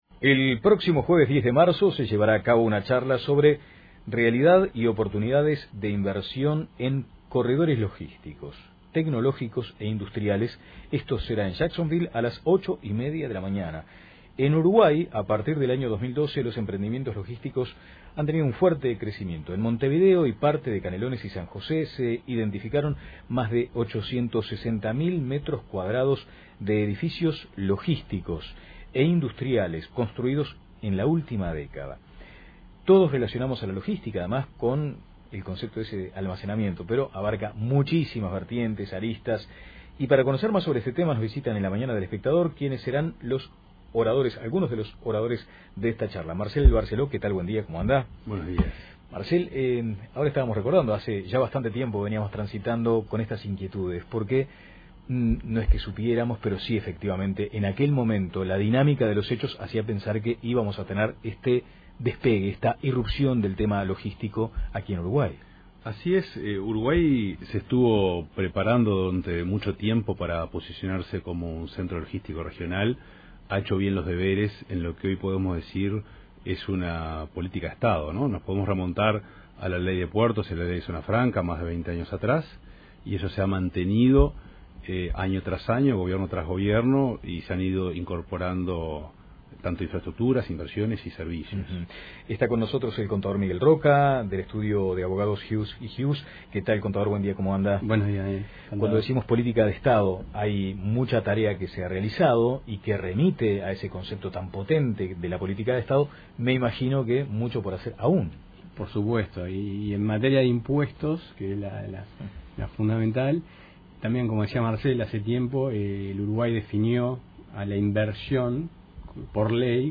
Escuche la entrevista completa aquí: Descargar Audio no soportado